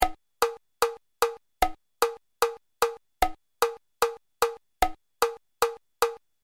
Für die Hörbeispiele habe ich einen einfachen 4/4 Takt gewählt.
vivace
lebhaft
BPM: 150